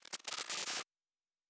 Sci-Fi Sounds / Electric / Glitch 3_03.wav
Glitch 3_03.wav